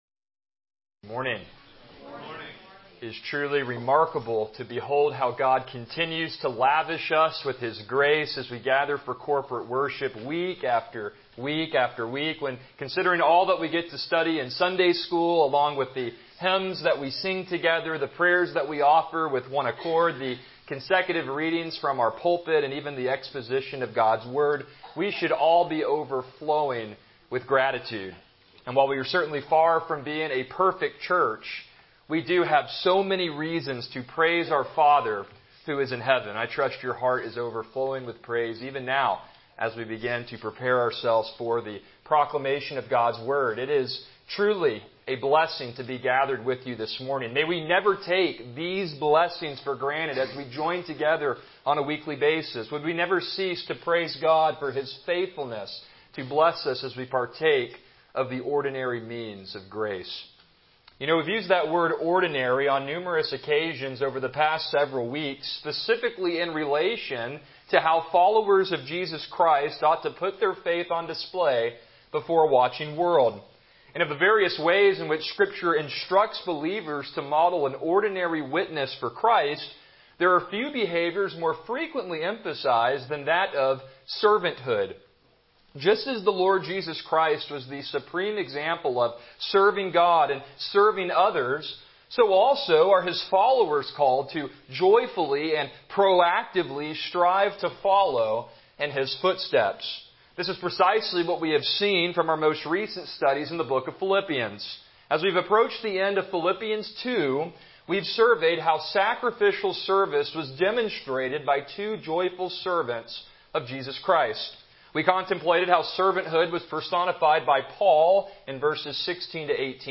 Passage: Philippians 2:25,30 Service Type: Morning Worship